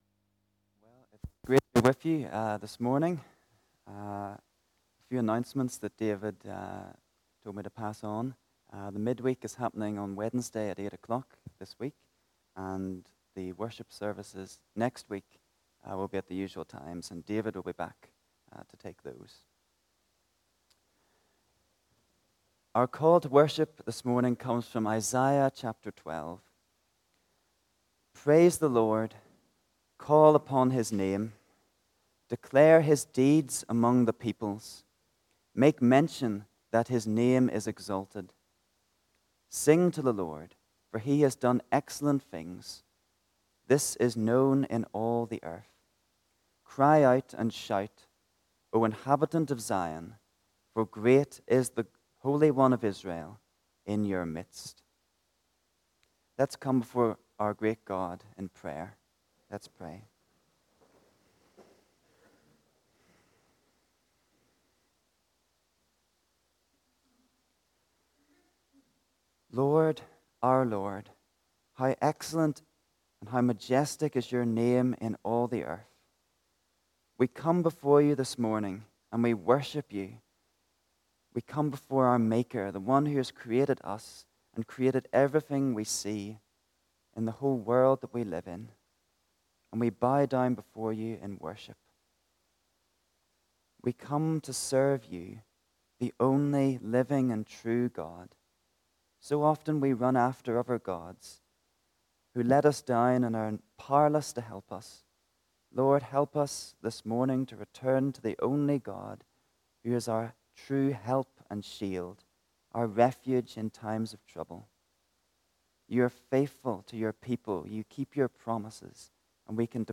Passage: Isiah ch59 Service Type: Morning Service